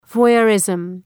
{vwɑ:’jɜ:rızm}